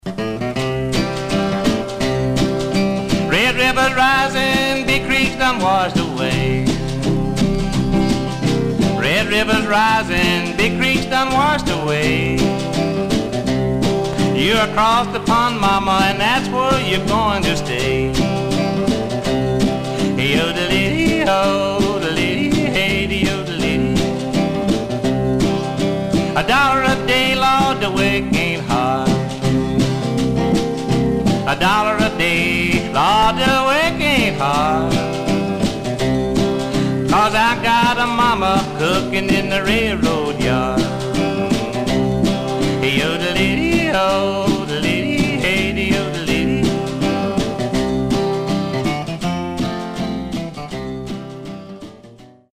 Some surface noise/wear
Mono
Country